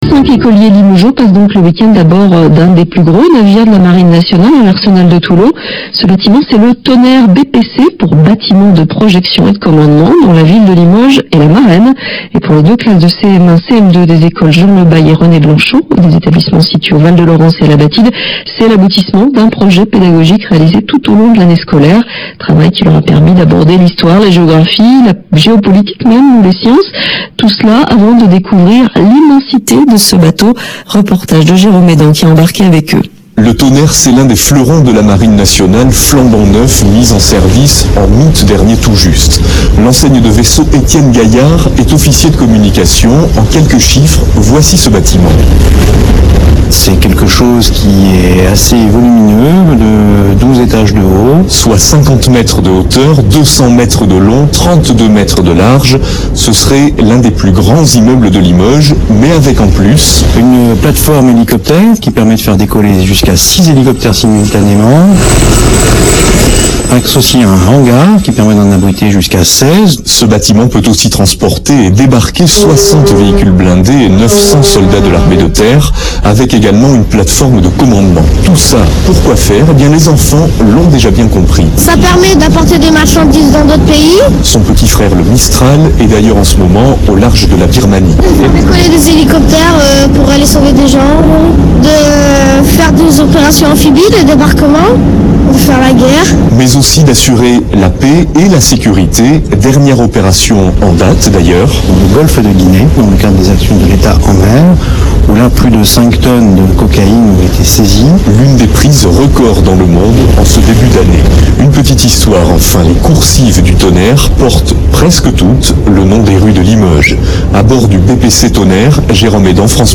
Reportage Journal France Bleu Limoges / 2 juin 2008